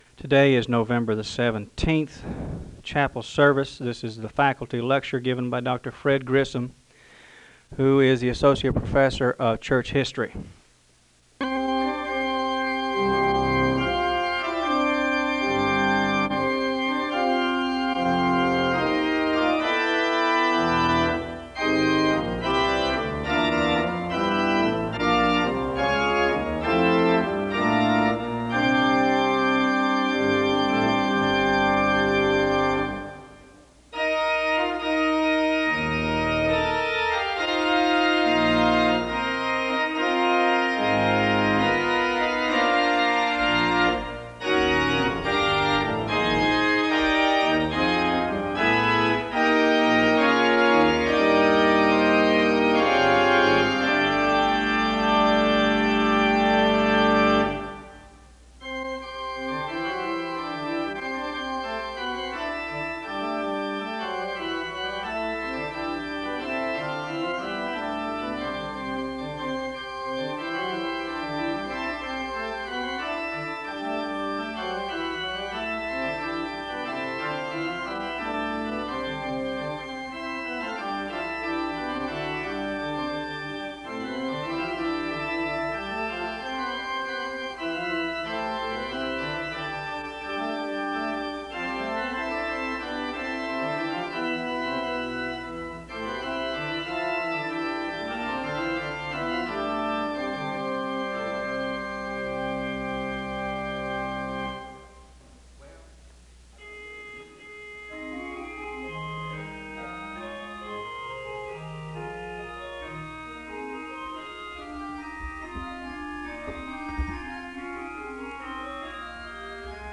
Hymns are played (0:11-7:59). A word of prayer is given (8:00-8:59).